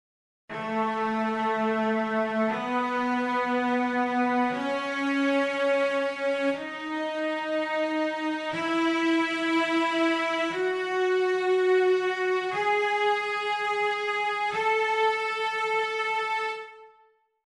Lydian Scale:
Click to hear the A Lydian scale.
lydian.mp3